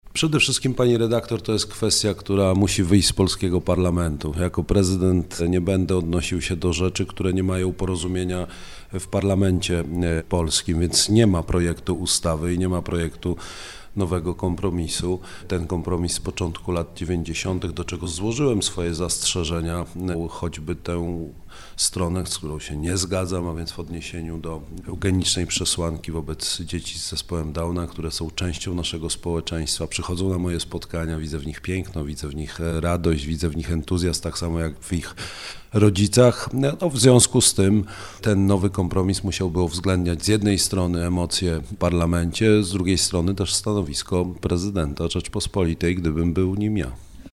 Karol Nawrocki – kandydat na urząd prezydenta popierany przez PiS był gościem Radia Rodzina. Rozmawialiśmy o patriotyzmie, kierunku polskiej edukacji, redukcji liczby godzin religii, światopoglądzie.